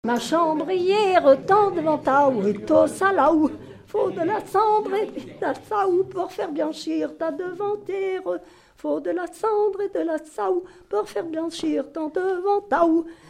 Mesnard-la-Barotière
danse : branle
Genre brève
Pièce musicale inédite